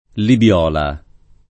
Libiola [ lib L0 la ]